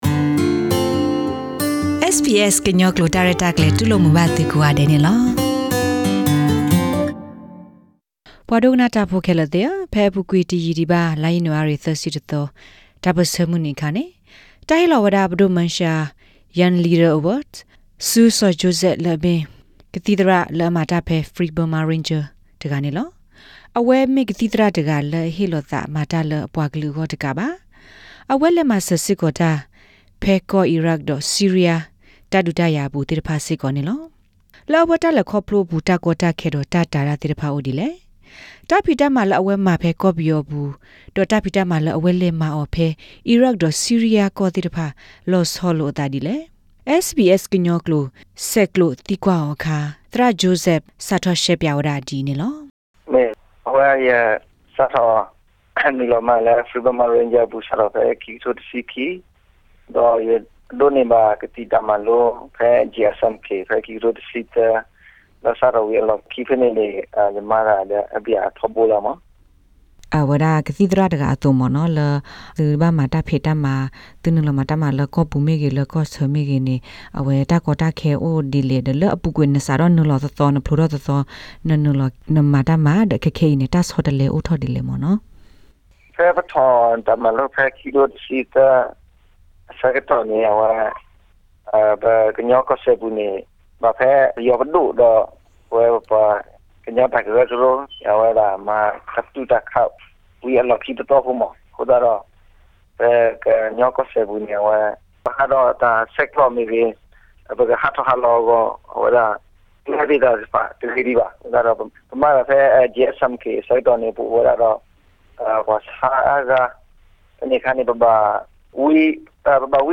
Interview: Karen medic tells his experiences of working in Myanmar, Iraq and Syria